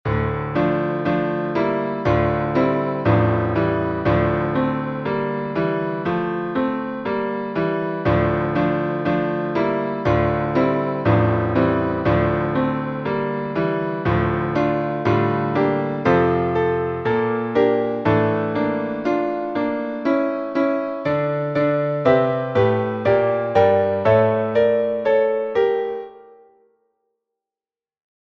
Meter: 8.8.8.8
Key: d minor or modal